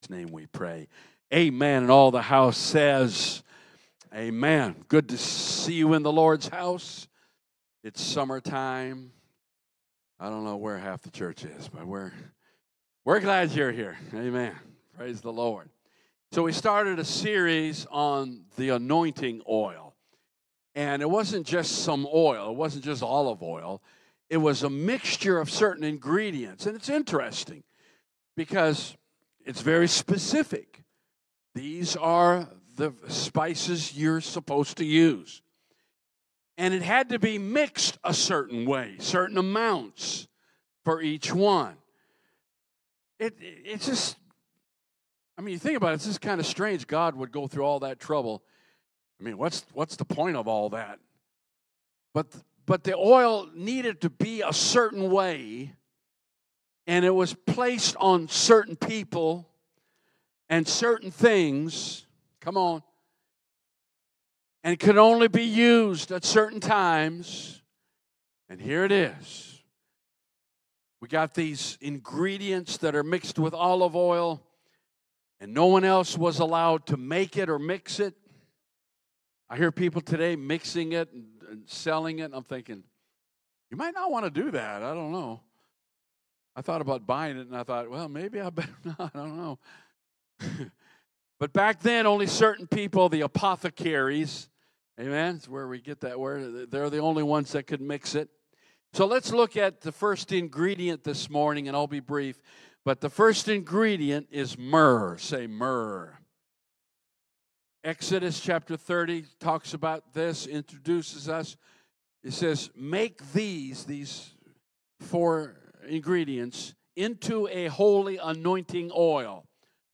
The recipe for the anointing begins with myrrh. Don't miss out on Sunday's message titled "Mixing Myrrh".